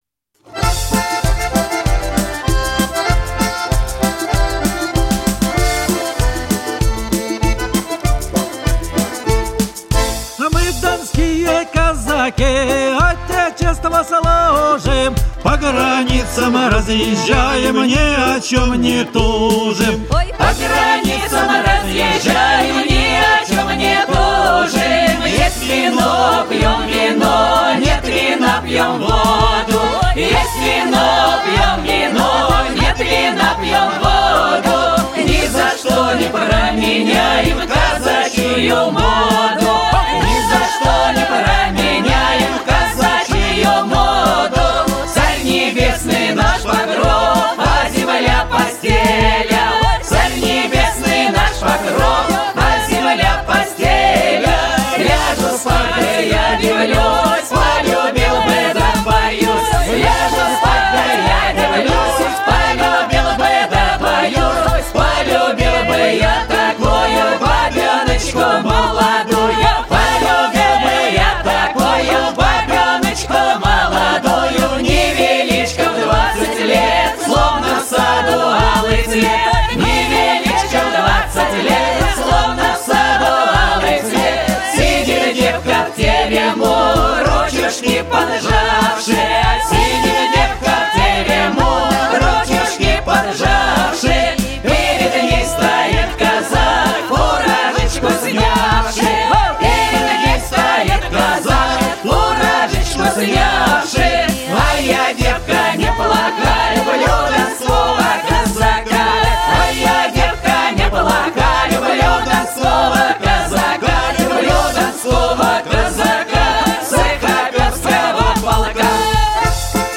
Коллектив почти год трудился в студии звукозаписи